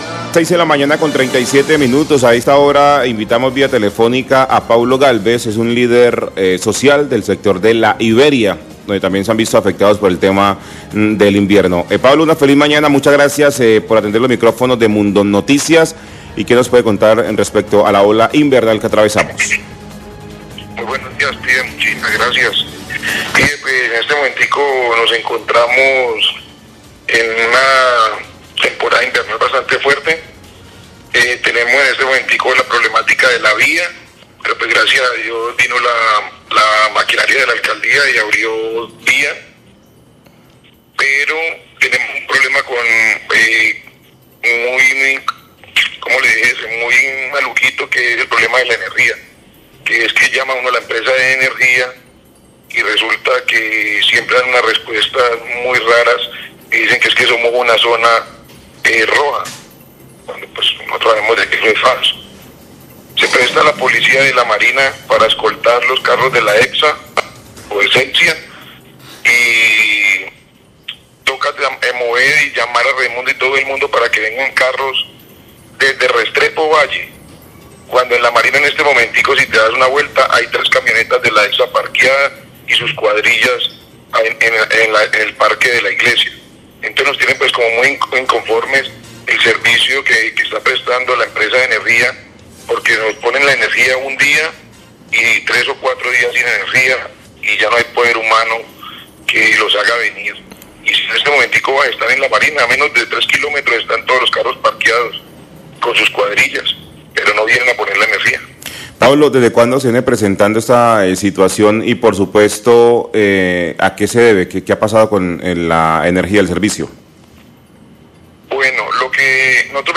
Oyente de La iberia se muestra muy inconforme con el servicio de energía, Mundo 89Fm, 640am
Radio
queja oyentes